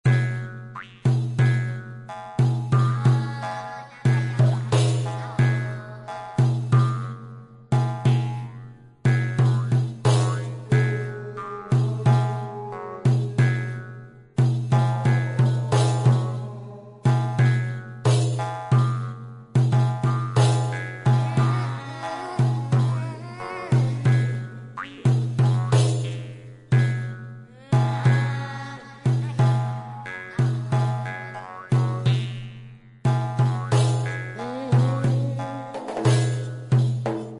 Tambourine Sounds download and listen online
• Category: Tambourine 1243